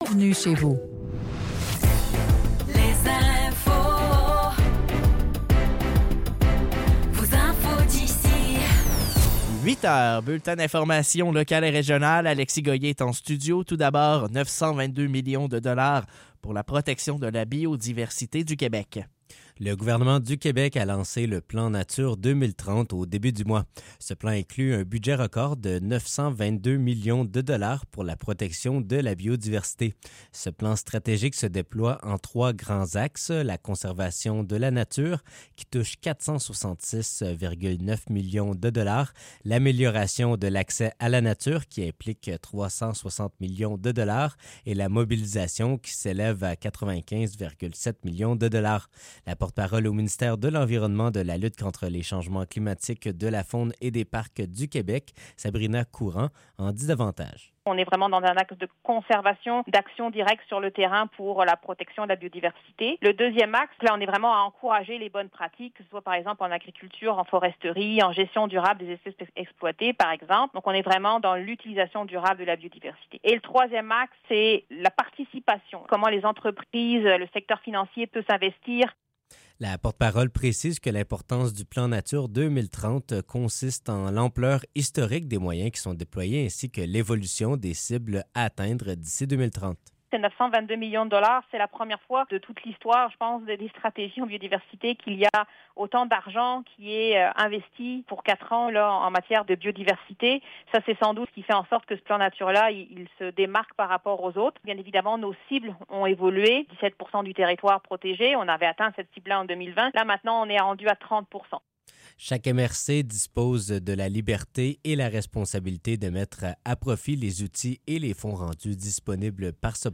Nouvelles locales - 16 octobre 2024 - 8 h